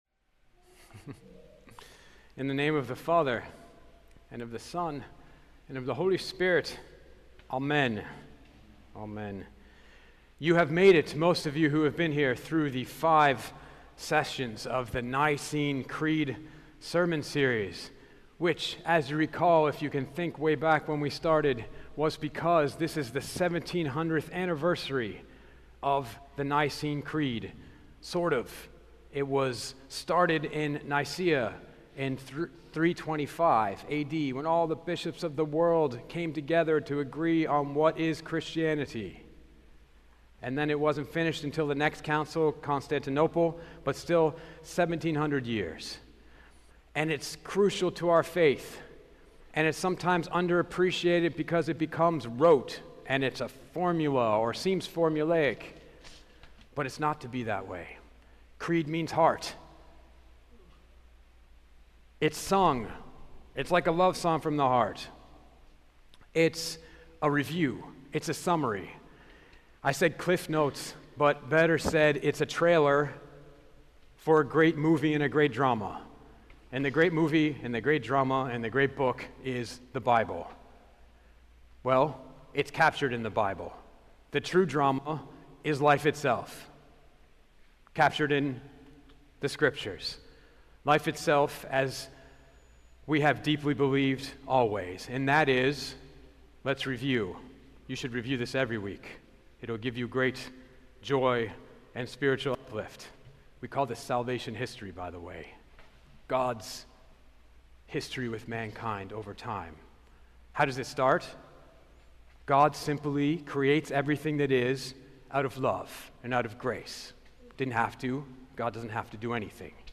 nicenecreed-session5.mp3